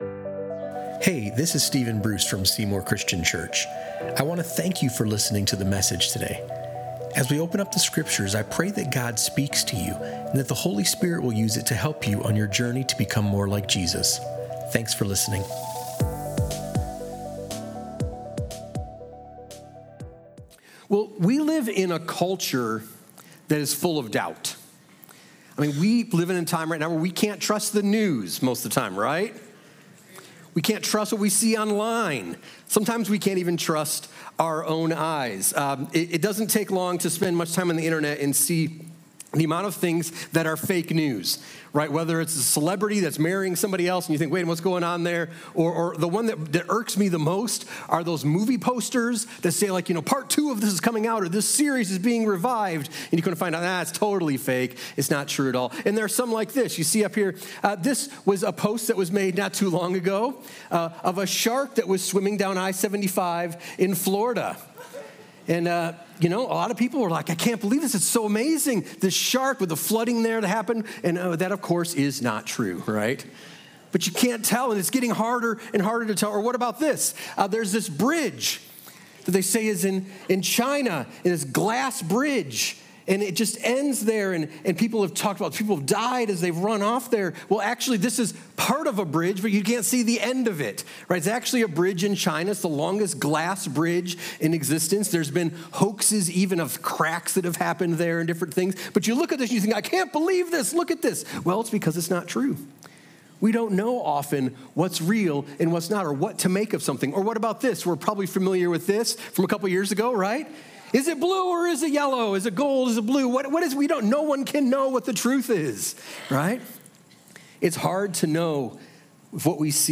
The first message in our series What to Do With Doubt: Faith in the Midst of Questions reminds us that doubt doesn’t mean denial. From the disciples in Matthew 28 to John the Baptist in prison and Thomas after the resurrection, Scripture shows that even heroes of faith struggled with questions. This message unpacks how to admit your doubts honestly, stay connected in community, and bring your questions to Jesus—because doubt can deepen, not destroy, your faith.